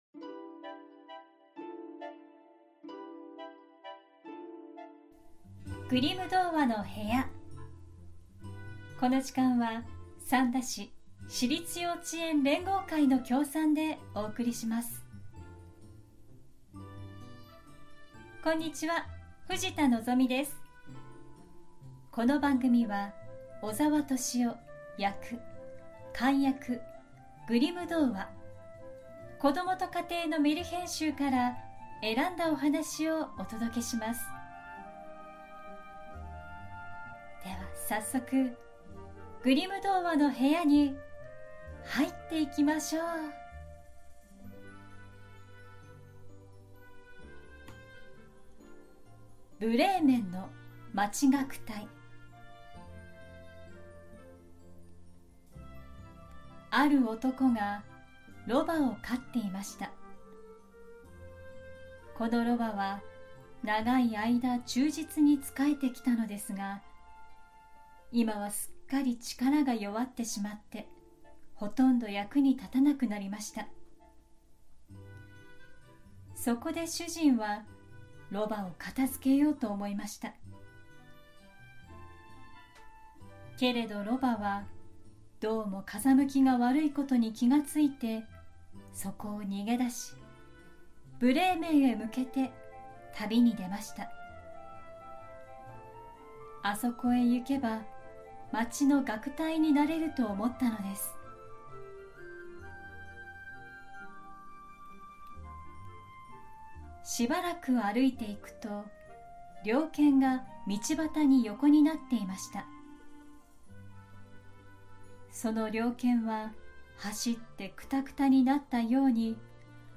グリム兄弟によって集められたメルヒェン（昔話）を、翻訳そのままに読み聞かせします📖 2026年最初の配信は『ブレーメンの町楽隊』。 それぞれに事情を抱えたロバ・犬・猫・にわとりがブレーメンで音楽隊になろうと共に行動し、最後は気に入った家で音楽を奏でながら仲良く暮らすというおなじみのお話です🐴 動物たちには哀愁を感じますが、何とか生き抜こうとする力強さに胸を打たれます✨ 動物が次から次へと登場するので小さなお子さんにも聴きやすいかもしれません♪ 昔話の本当のストーリーを、お子さんも大人の方もどうぞお楽しみください✨✨